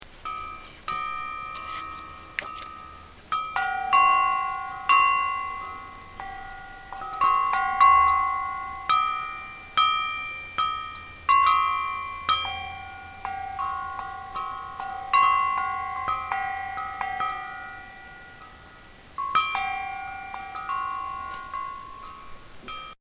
This wind bell is reflective in three sounds of sea bells, gongs and bell buoys off shore.